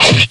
Slash7.ogg